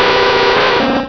cries
crobat.wav